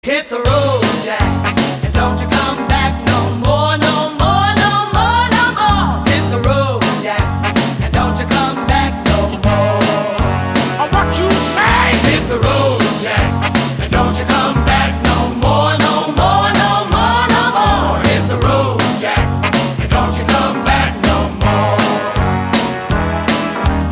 • Качество: 256, Stereo
джайв
Jive
Композиция в ритме джайв